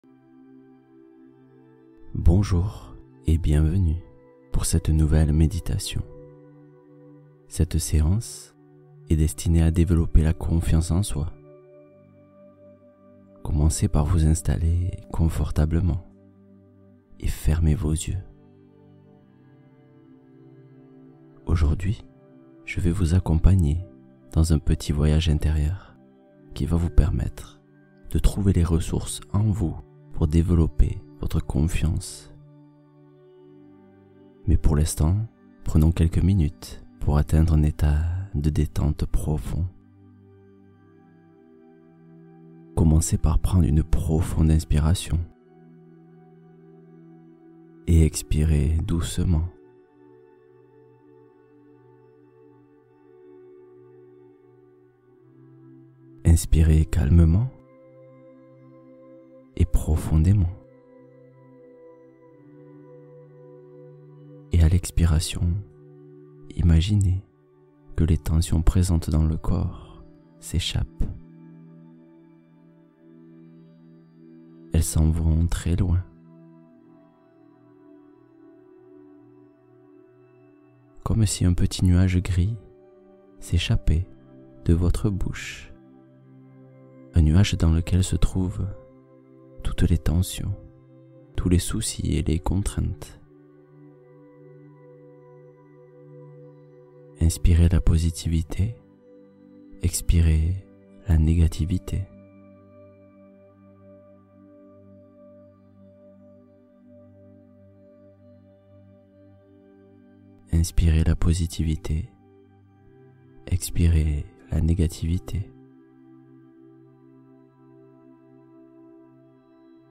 Visualisation guidée : exercice puissant pour amplifier la confiance